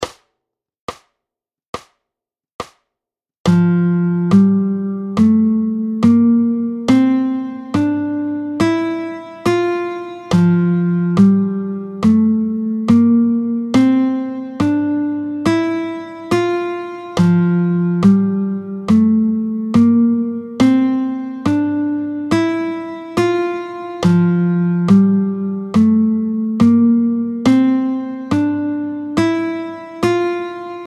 13-04 Les sept accords construits sur la gamme de Ré, tempo 70